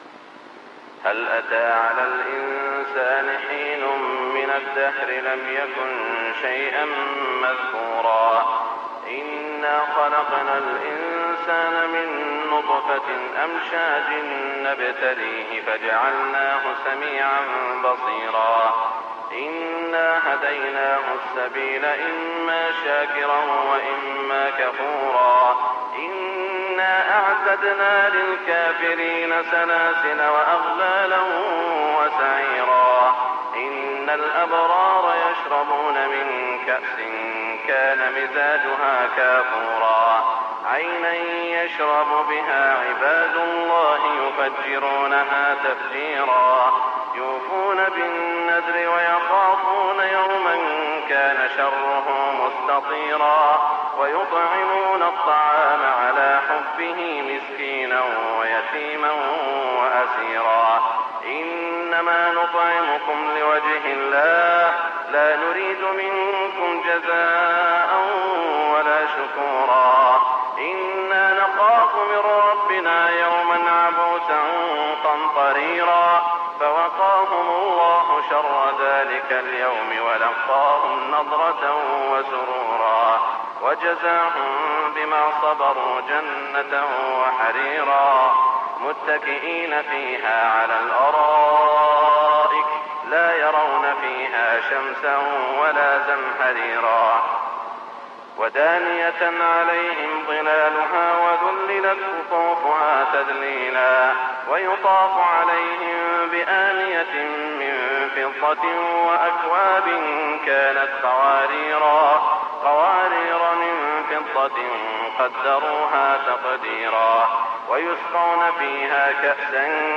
صلاة الفجر 1421 من سورة الإنسان > 1421 🕋 > الفروض - تلاوات الحرمين